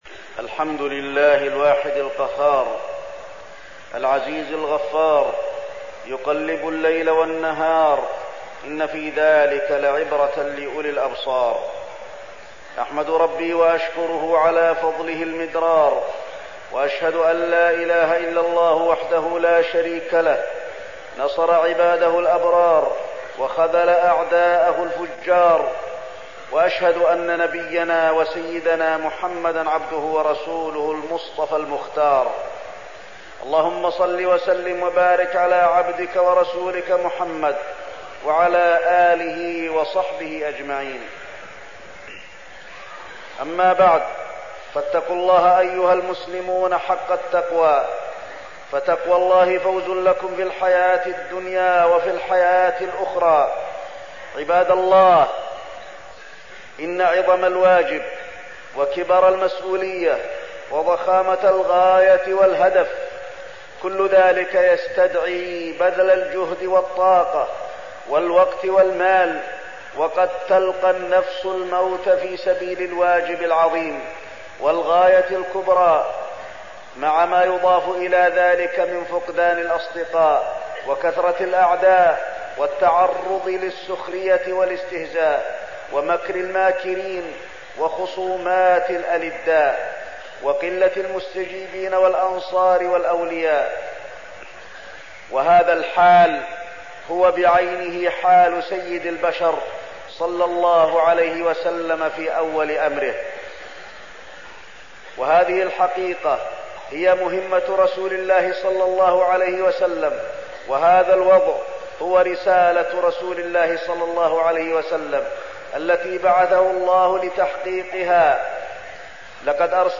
تاريخ النشر ٣ محرم ١٤١٨ هـ المكان: المسجد النبوي الشيخ: فضيلة الشيخ د. علي بن عبدالرحمن الحذيفي فضيلة الشيخ د. علي بن عبدالرحمن الحذيفي الهجرة النبوية